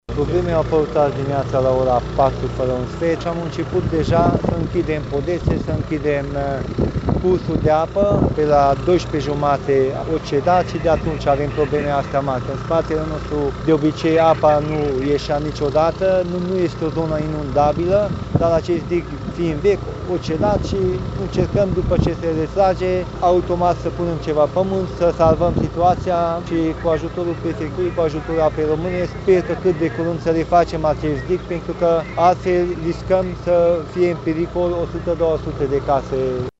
Primarul din Sânpaul, Simon Istvan: